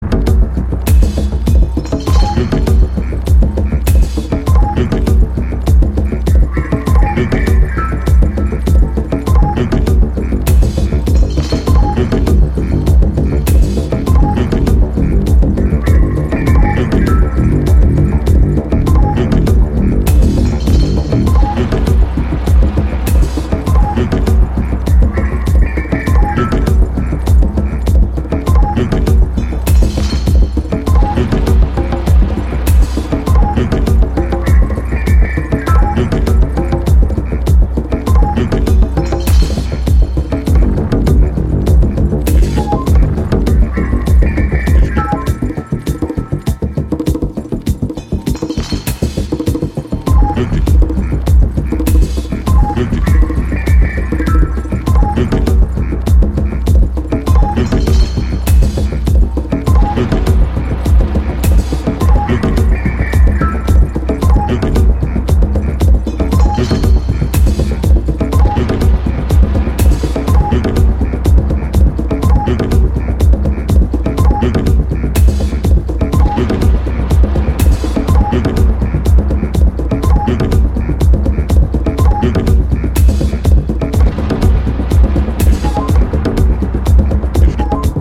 ピッチの変化で空気を操る